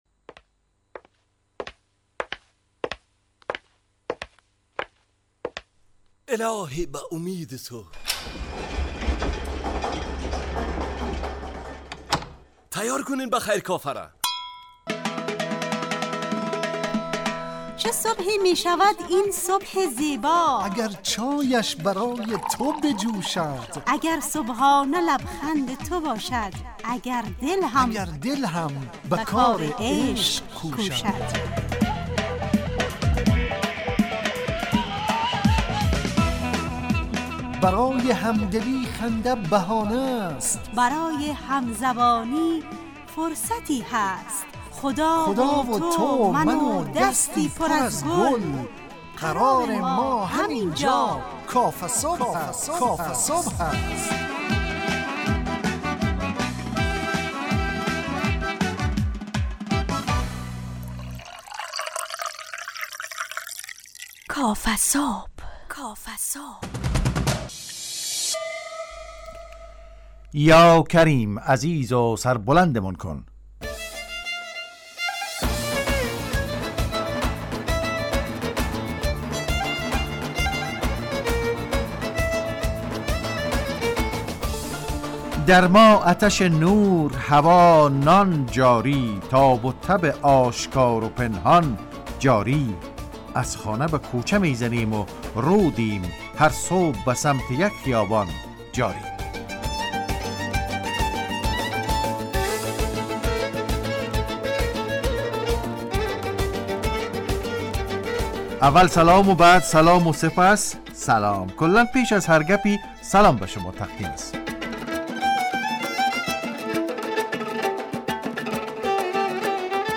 کافه‌صبح – مجله‌ی صبحگاهی رادیو دری با هدف ایجاد فضای شاد و پرنشاط صبحگاهی
با بخش‌های کارشناسی، نگاهی به سایت‌ها، گزارش، هواشناسی٬ صبح جامعه، گپ صبح و صداها و پیام‌ها شنونده‌های عزیز